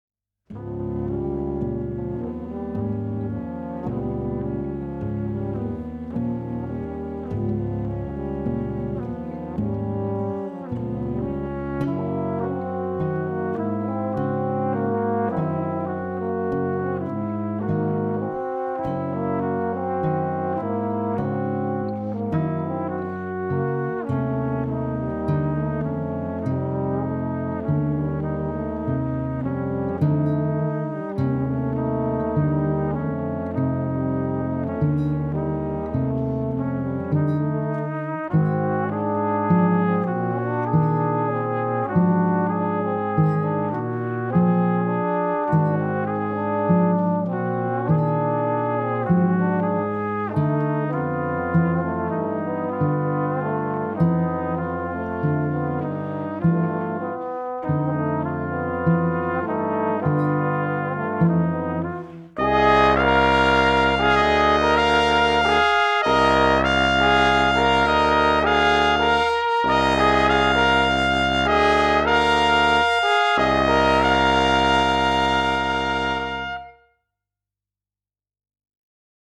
Jazz Music and More